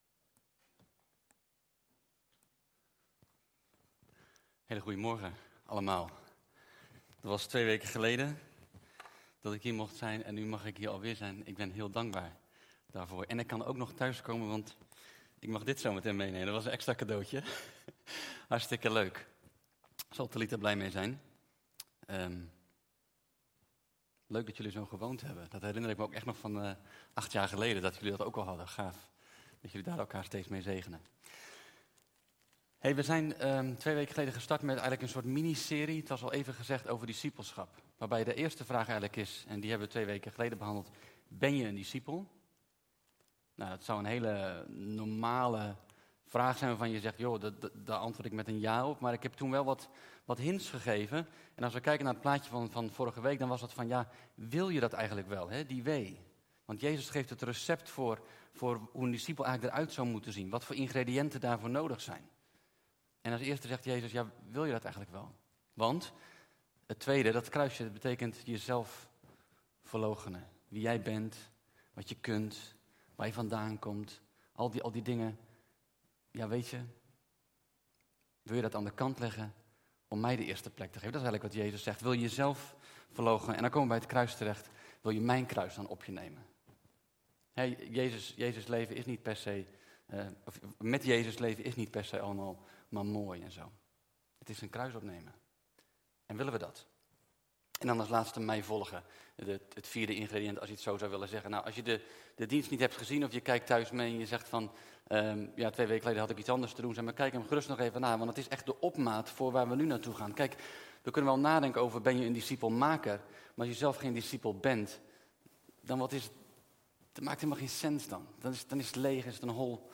Toespraak 29 november: Ben jij een discipel-maker - De Bron Eindhoven